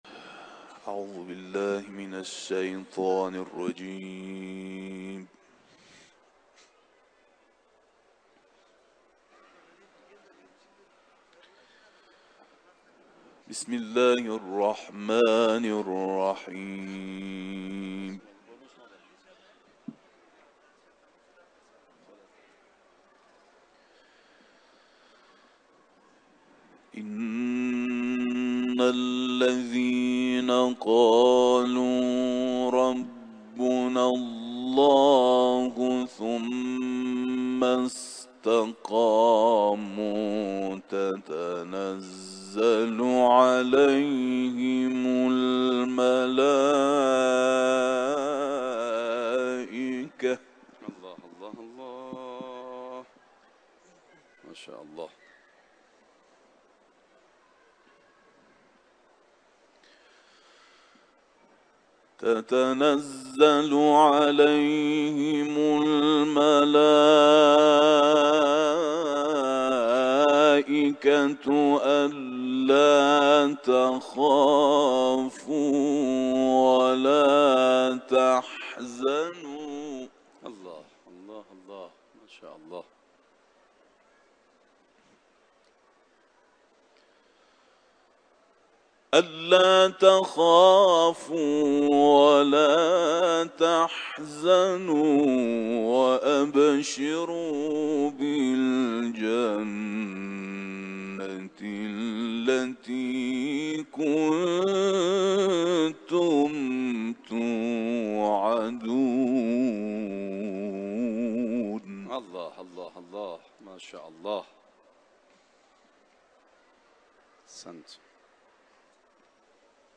Kur’an-ı Kerim tilaveti